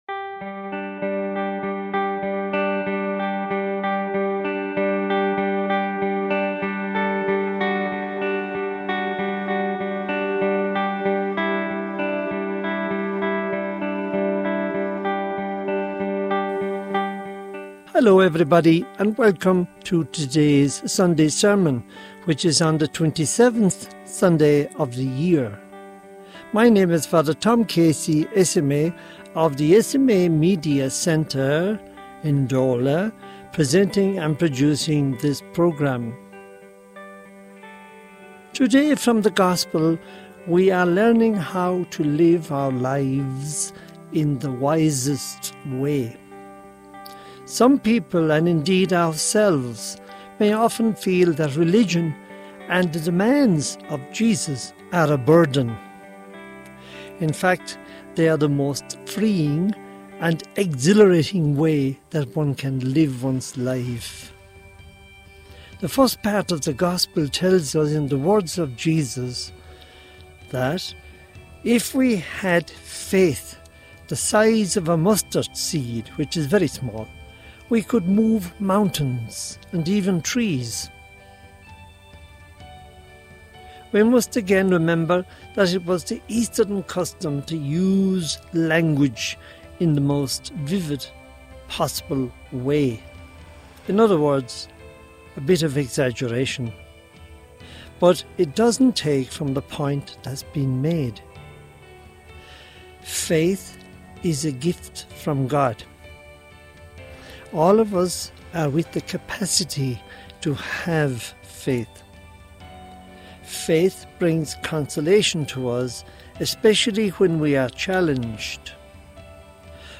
Homily for the 27th Sunday of Ordinary Time, 2025 | Society of African Missions